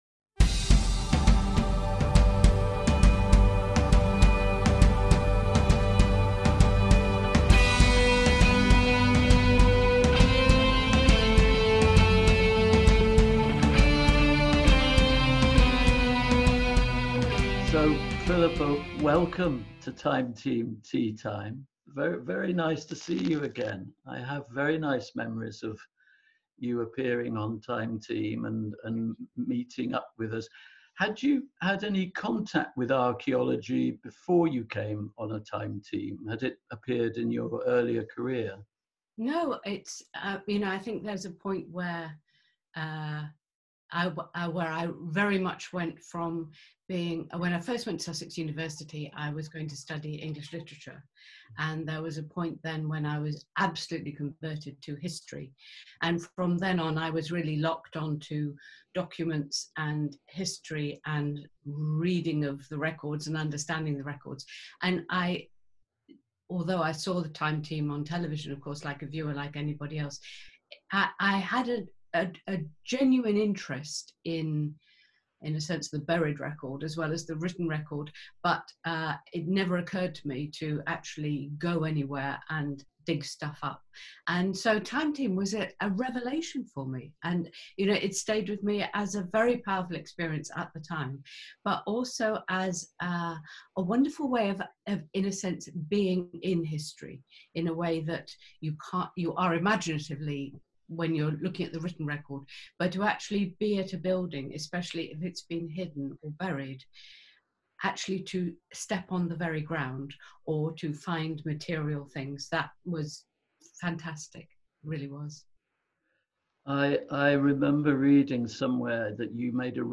We revisit an interview with queen of historic fiction, Philippa Gregory CBE, with an extended chat including fascinating and previously unseen insights. Philippa discusses her bestselling novels, and experiences on Time Team at Syon House and Groby Old Hall – home of the ‘White Queen’, Elizabeth Woodville.